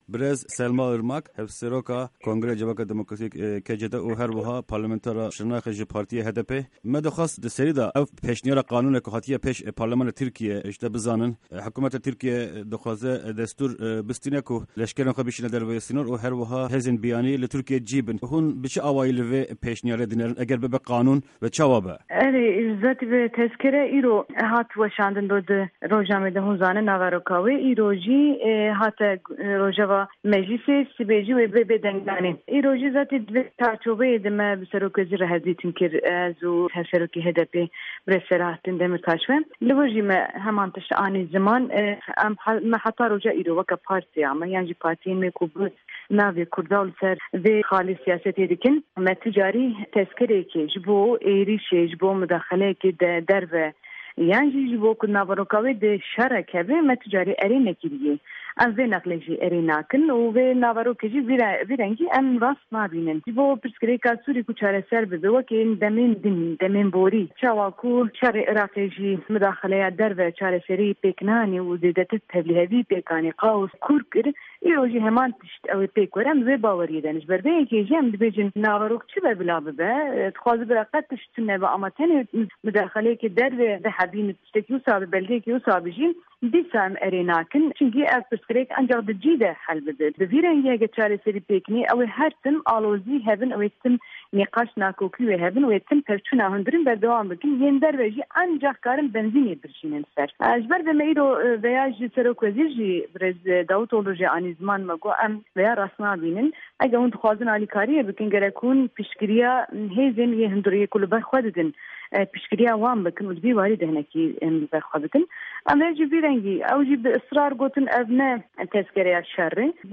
Di hevpeyvîna Dengê Amerîka de Selma Irmak, Hevseroka Kongreya Civaka Demokratîk (KCD) û parlamentera Şirnêxê li ser pêşnîyara qanûna li ser Sûrîyê û Îraqê agahîya dide.